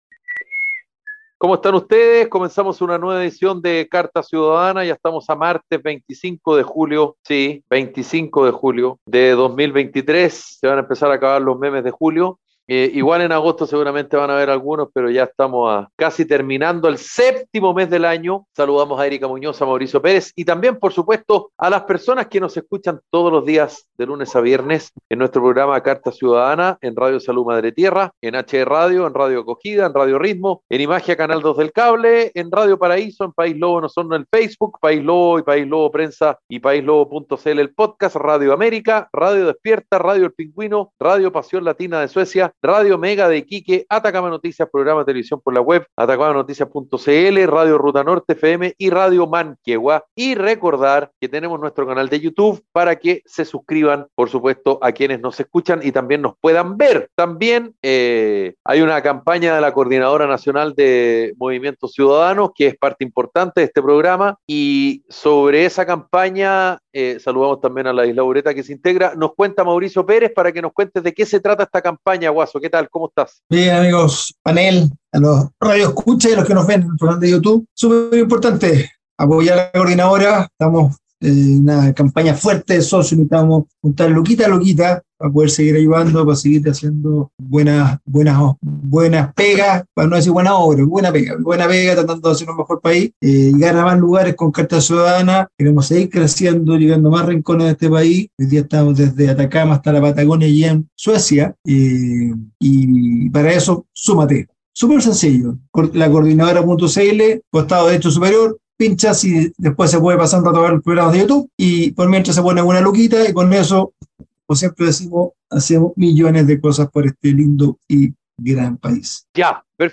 Un programa radial de conversación y análisis sobre la actualidad nacional e internacional.